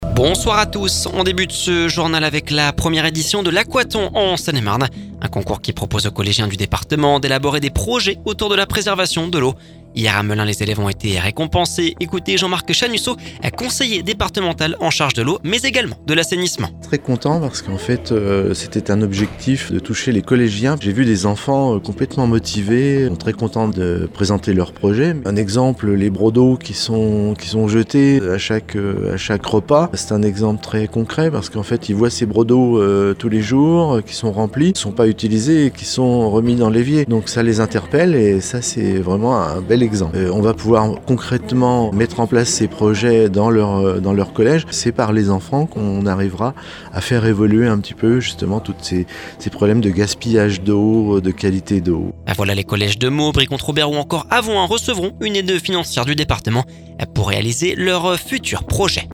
Écoutez Jean-Marc Chanussot conseiller départemental en charge de l’eau et de l’assainissement…